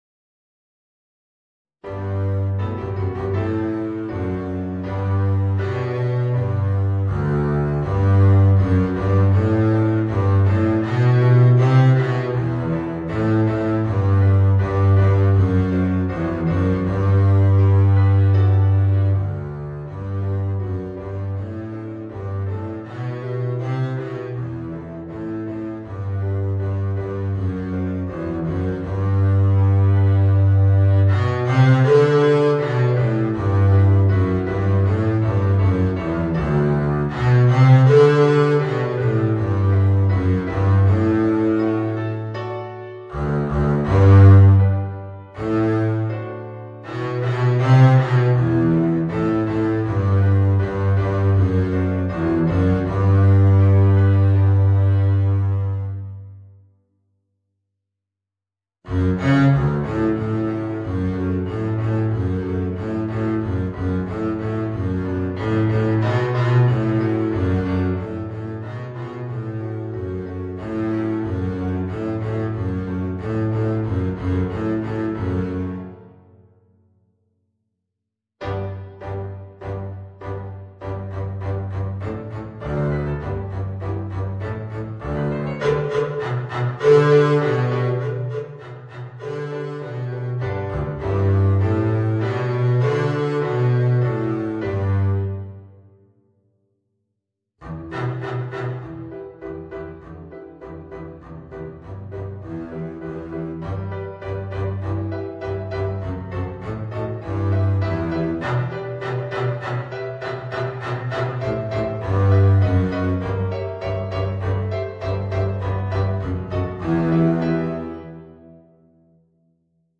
Contrabass